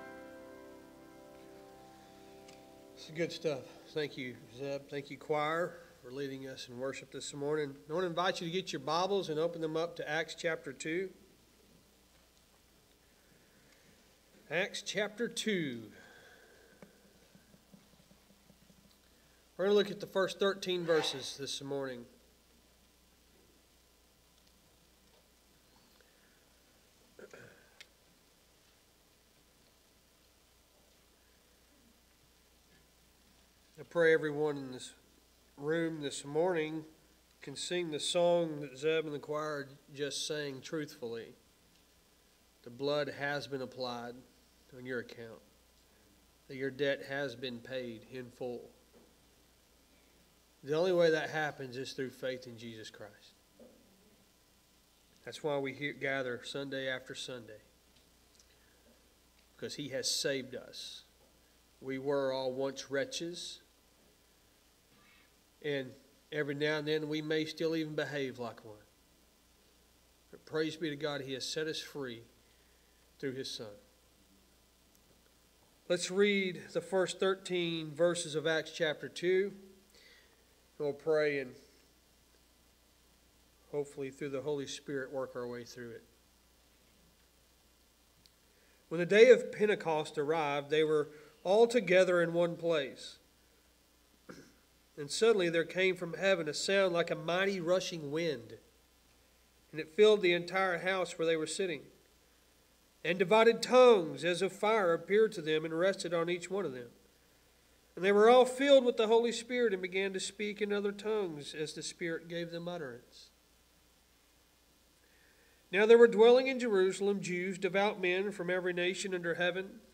Sermons | Lake Athens Baptist Church